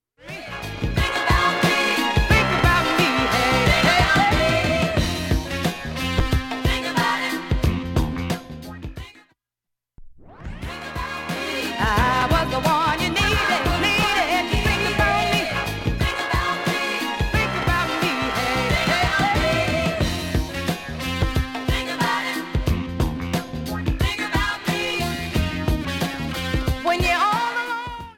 盤面きれいで音質良好全曲試聴済み。
A-4後半に一箇所針飛び修正あります。
試聴は針圧３Gのものを先に、
当方テクニクスプレーヤーで、針はSHURE 44Gです。
込み上げるグレイトメロウダンサー